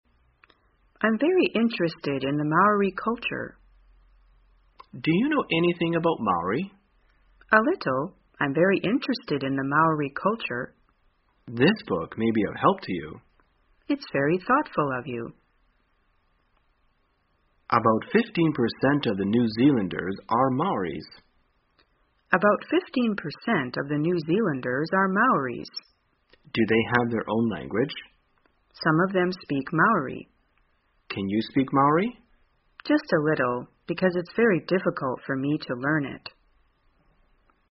在线英语听力室生活口语天天说 第357期:怎样谈论毛利文化的听力文件下载,《生活口语天天说》栏目将日常生活中最常用到的口语句型进行收集和重点讲解。真人发音配字幕帮助英语爱好者们练习听力并进行口语跟读。